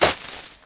Index of /global/sound/snowball
hit.mp3